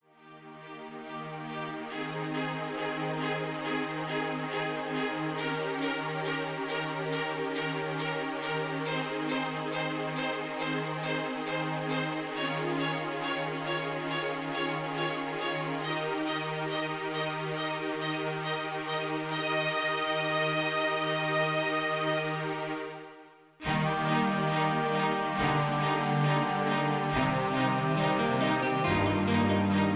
Karaoke MP3 Backing Tracks
Just Plain & Simply "GREAT MUSIC" (No Lyrics).
mp3 backing tracks